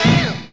JAZZ STAB 26.wav